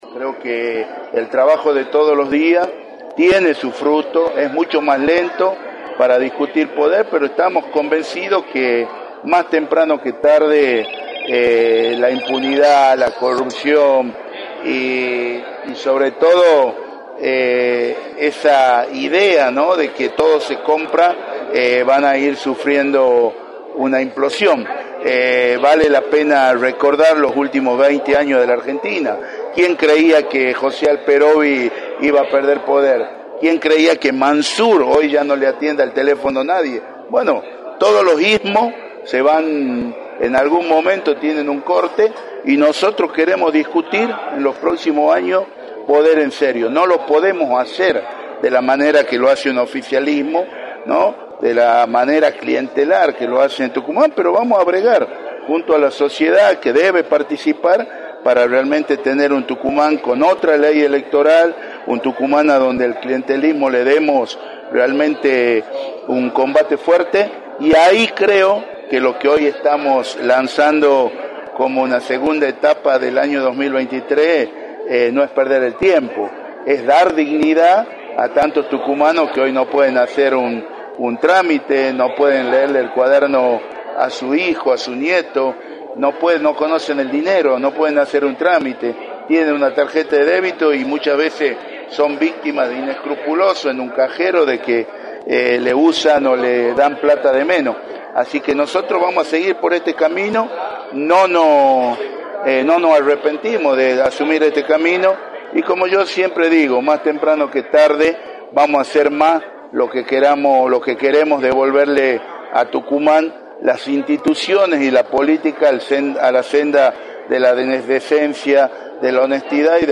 “Vamos a seguir por este camino, más temprano que tarde, vamos a ser más los que queremos devolverle a Tucumán las instituciones, en la senda de la honestidad y de la transparencia, queremos que a esto lo tomen como política de Estado a esto para que sean más los tucumanos que aprendan a leer y escribir, de eso se trata” señaló Federico Masso en entrevista para “La Mañana del Plata”, por la 93.9.